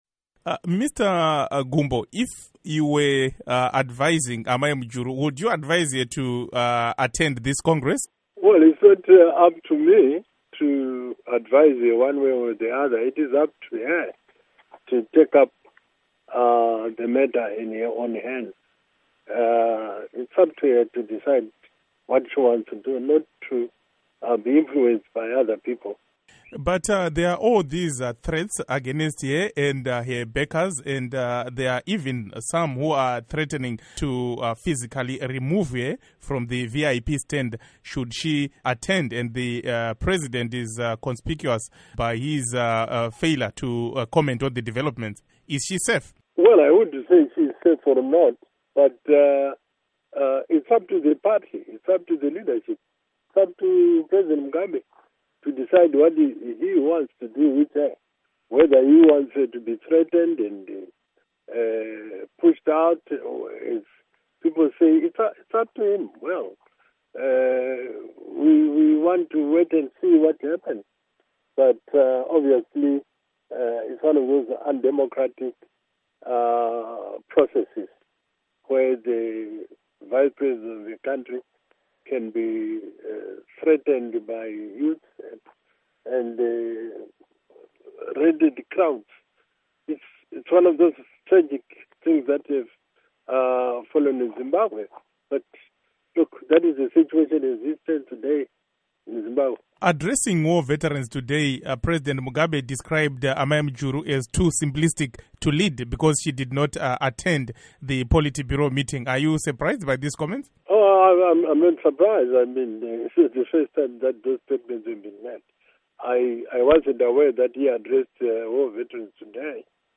Gumbo, who was interviewed before the latest developments, told VOA Studio 7 that the move to amend the constitution is against the party principles and is one of the reasons he fell out of favor with Mugabe as he vehemently opposed it.
Interview With Rugare Gumbo